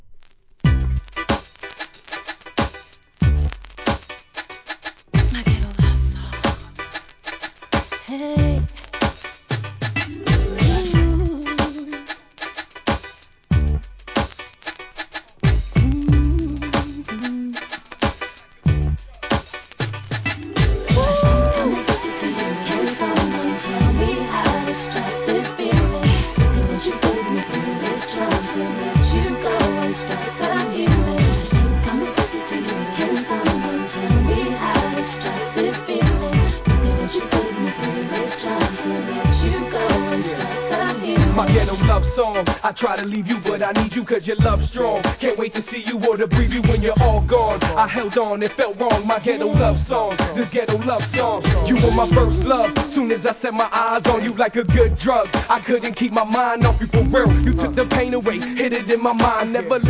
HIP HOP/R&B
キャッチーなRAP,ボーカルグループ！